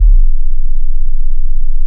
30SYN.BASS.wav